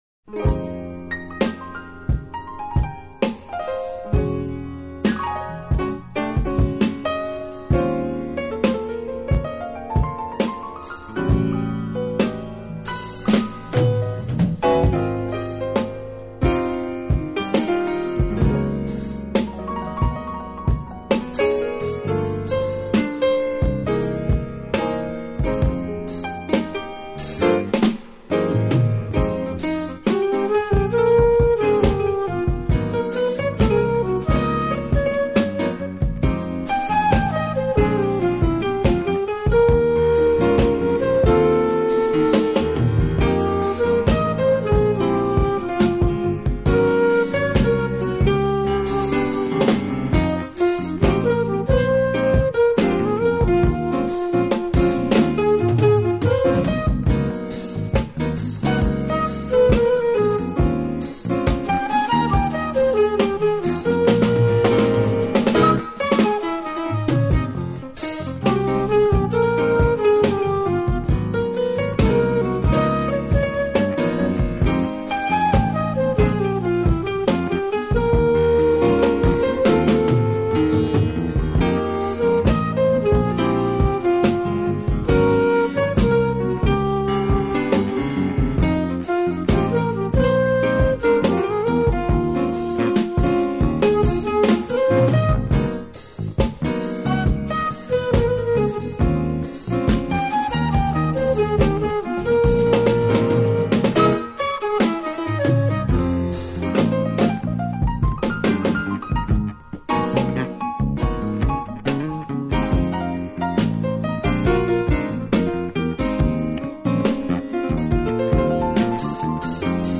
融合了了放克(Funk)，R&B，Fusion，摇滚和电声爵士等音乐元素，键盘手